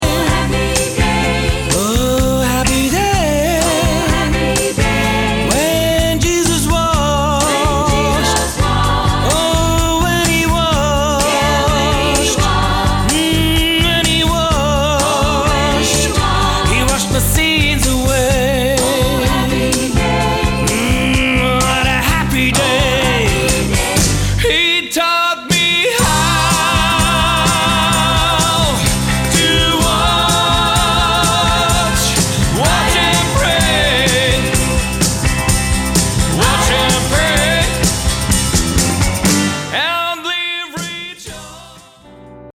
Gospels oder andere Songs während der Trauung
Gospel bei Trauung
Gefühlvoll - Echt - Gänsehaut feeling par excellence